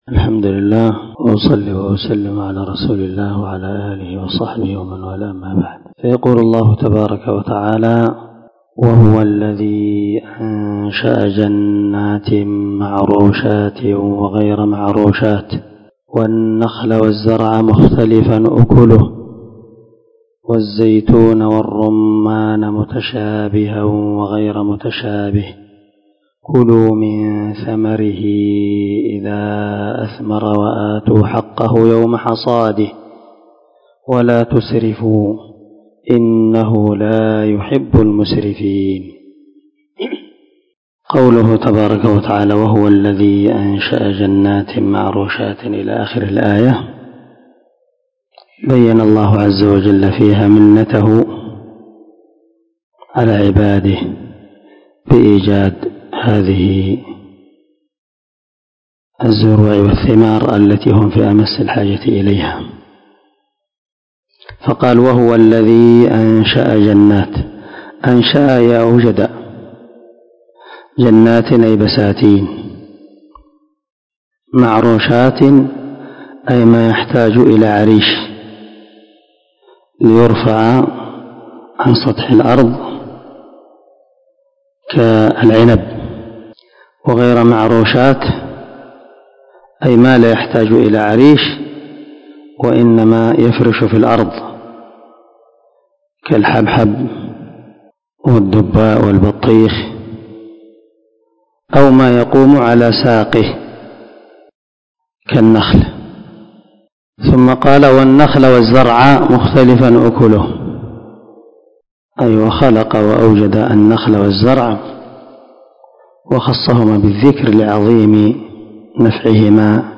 439الدرس 47 تفسير آية ( 141 ) من سورة الأنعام من تفسير القران الكريم مع قراءة لتفسير السعدي